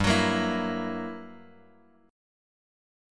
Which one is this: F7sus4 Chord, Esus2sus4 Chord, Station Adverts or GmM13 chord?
GmM13 chord